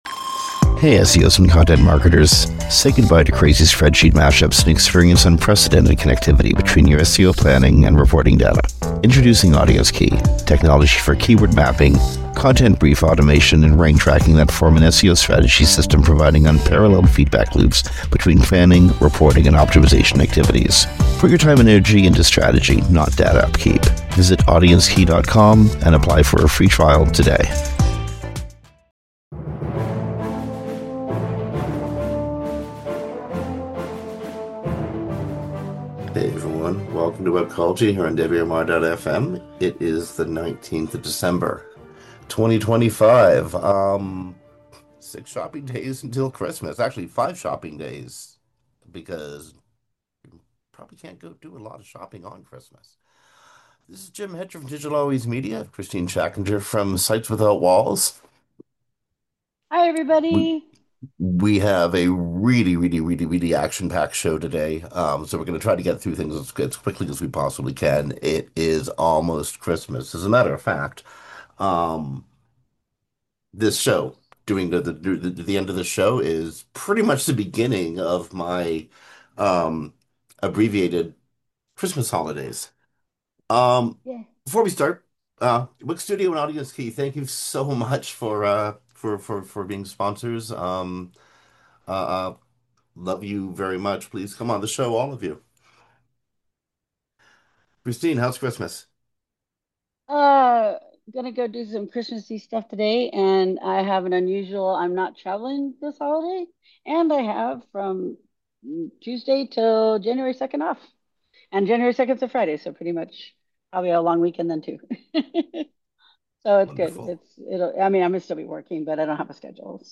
Based on interviews with special high-profile guests or panels, Webcology introduces, explores and explains how the various segments of the web marketing world work.